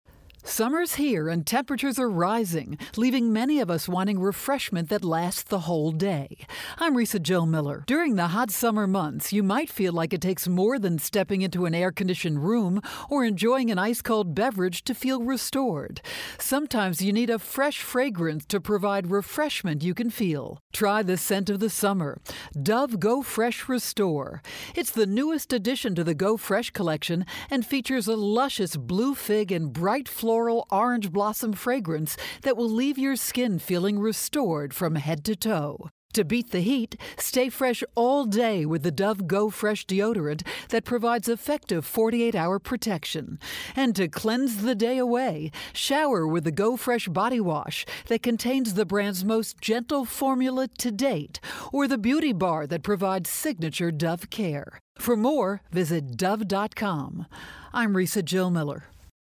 August 1, 2013Posted in: Audio News Release